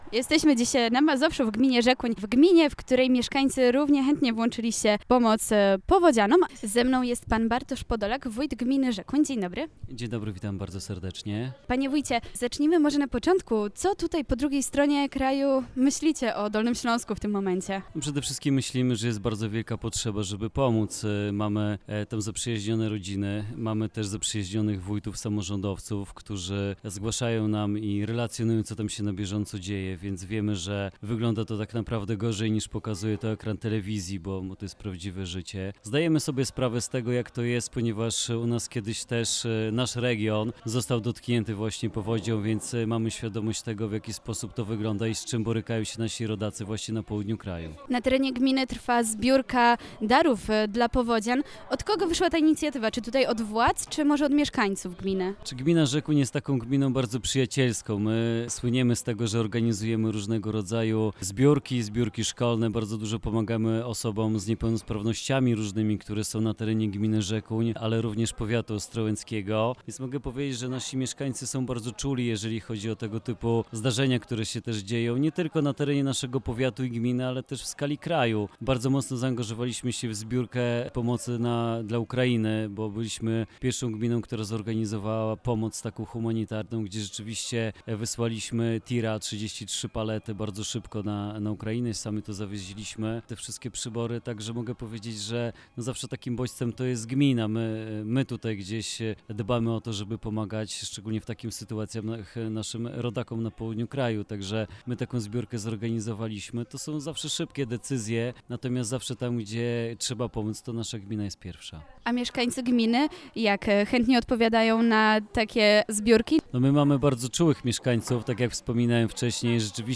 01_wojt-gminy-Rzekun_NW.mp3